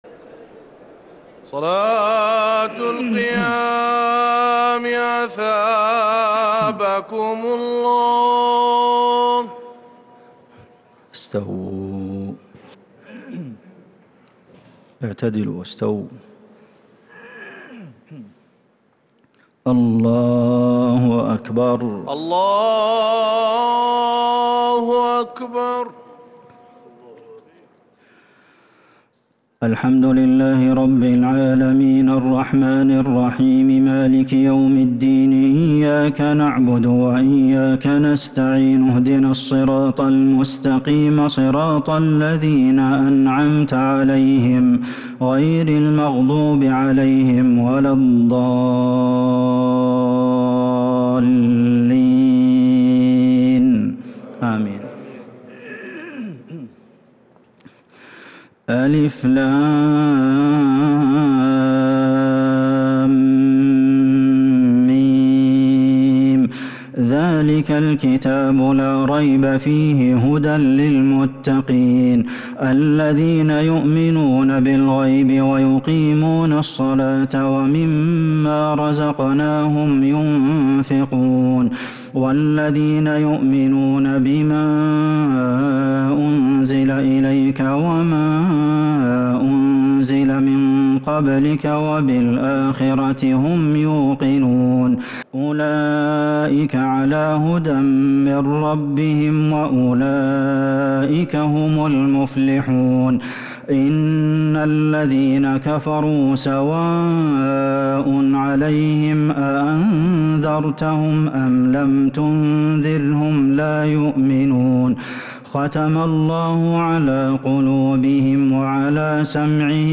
تراويح ليلة 30 رمضان 1442ھ من سورة البقرة (1-66) Taraweeh 30st night Ramadan 1442H > تراويح الحرم النبوي عام 1442 🕌 > التراويح - تلاوات الحرمين